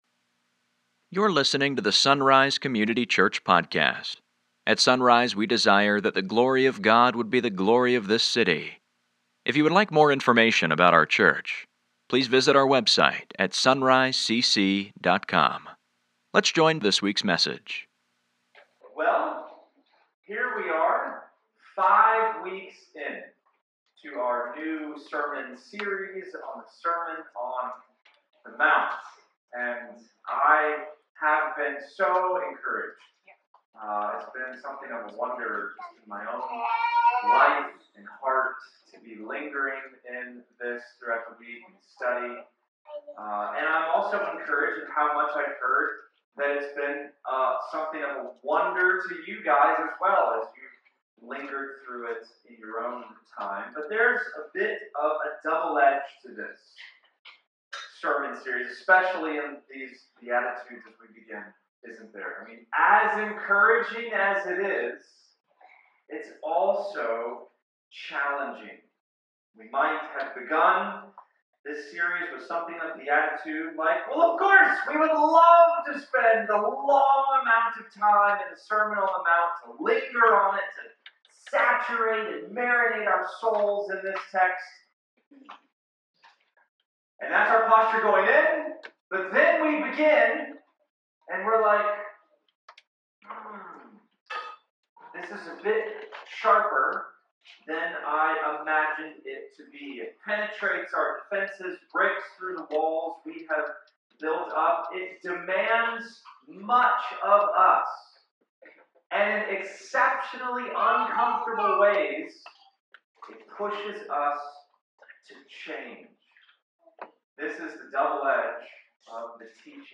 Sunday Mornings | SonRise Community Church